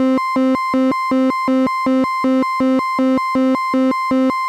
電卓、ミュージックシーケンサー搭載の電子キーボード VL-1
◆ADSR-ElectroSound1-Low-t01